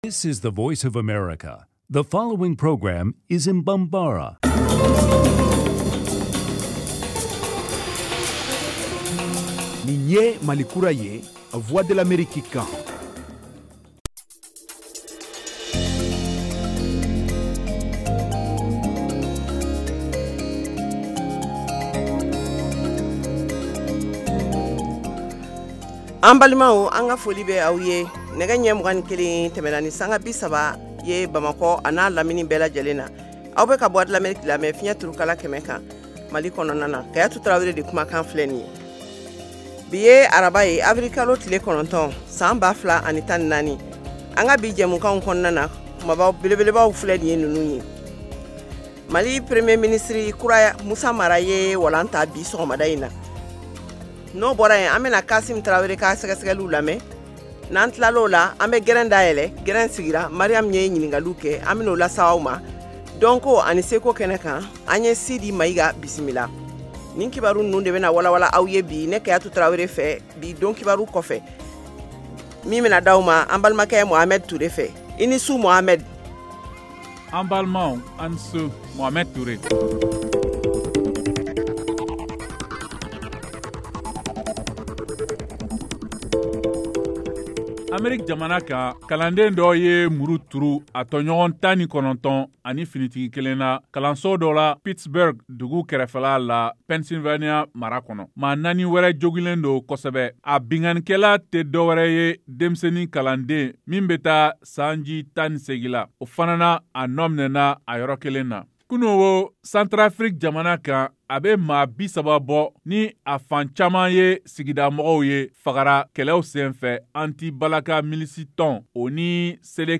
en direct de Washington. Au menu : les nouvelles du Mali, les analyses, le sport et de l’humour.